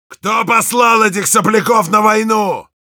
Sounds Yell Rus
Heavy_yell10_ru.wav